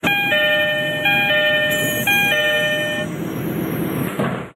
1100DoorOpen.ogg